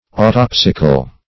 Search Result for " autopsical" : The Collaborative International Dictionary of English v.0.48: Autopsic \Au*top"sic\, Autopsical \Au*top"sic*al\, a. Pertaining to autopsy; autoptical.